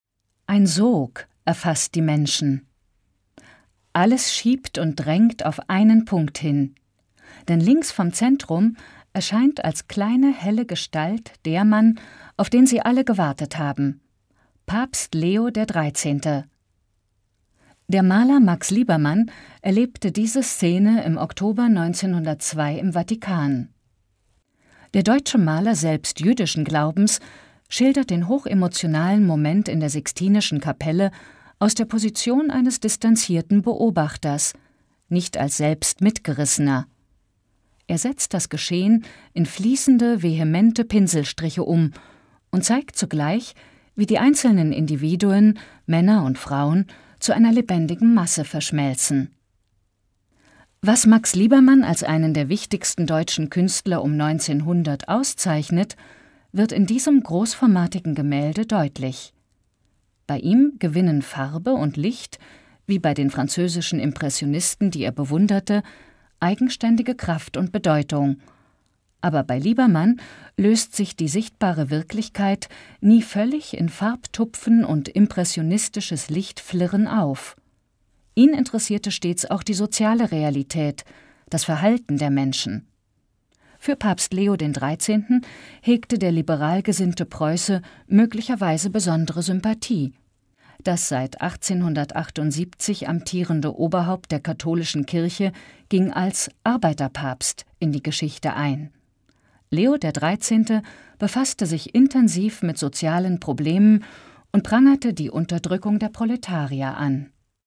Sprechproben
Sprecherin